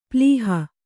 ♪ plīha